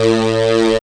5005R SYNTON.wav